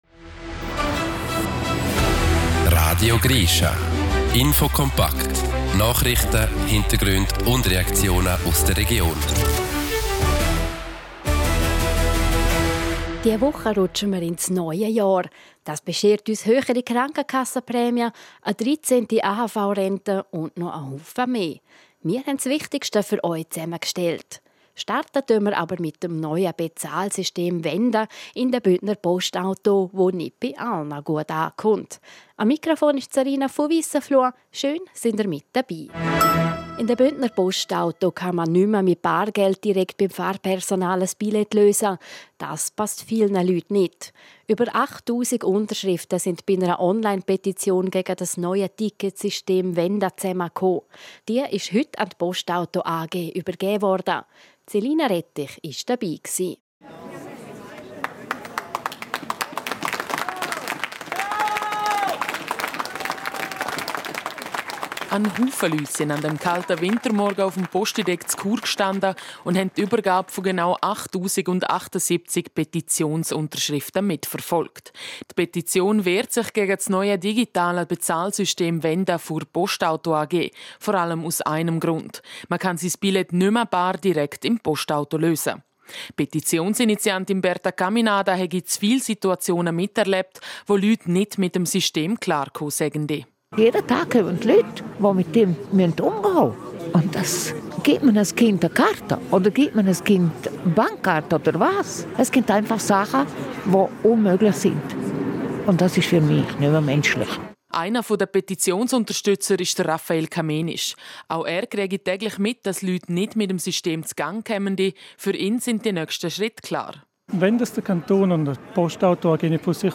Meldungen des Tages